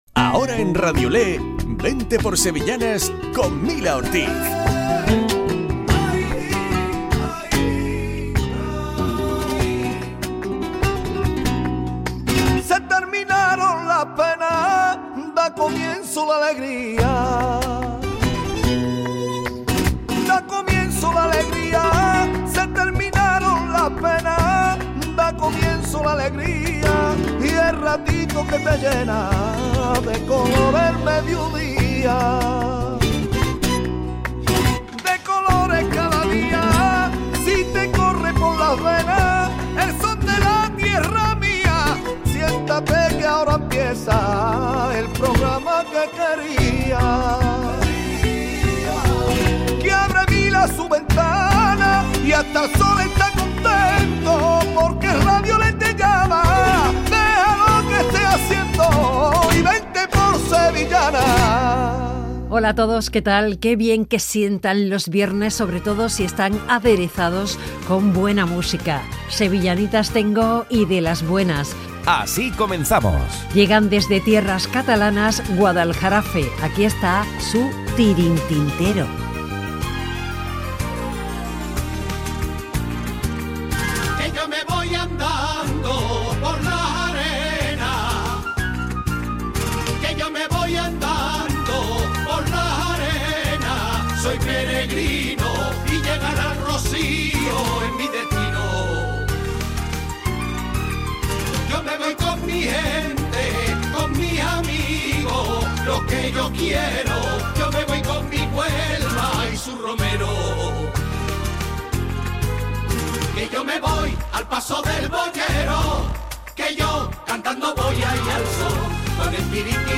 Programa dedicado a las sevillanas. Hoy con entrevista al grupo Media Luna.